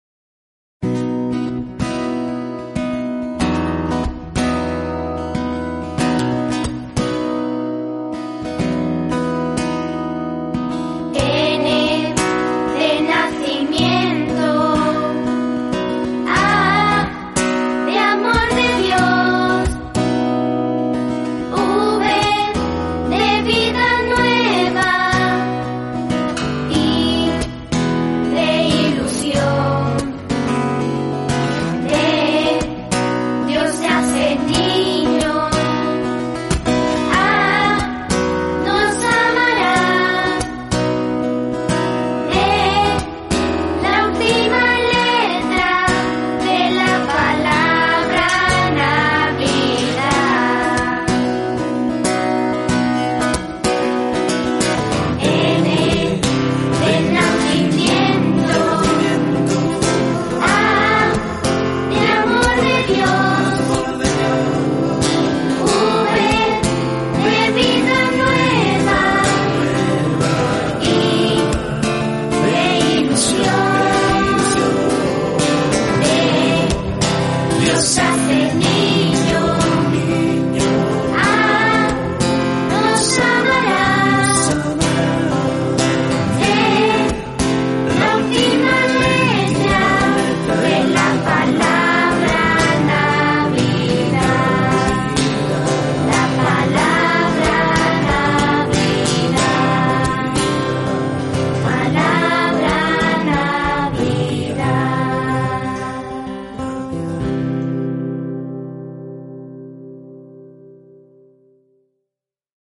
• VILLANCICO: Larga Navidad.